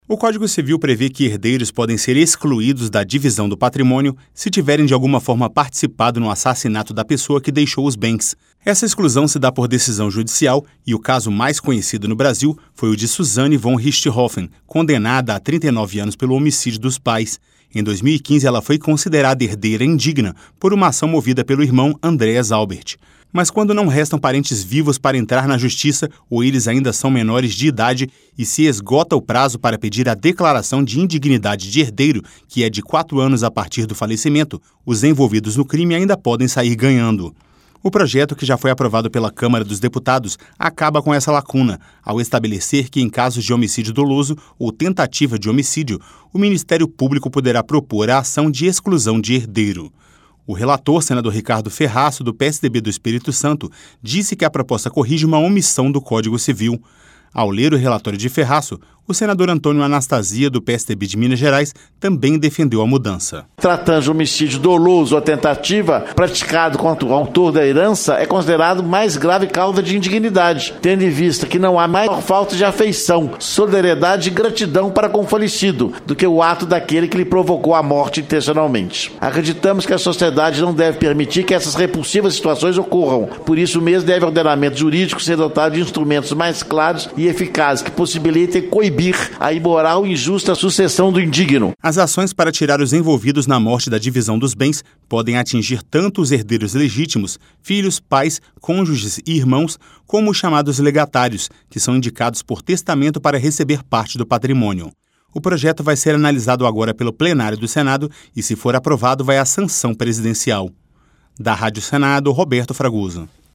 Ouça os detalhes no áudio do repórter da Rádio Senado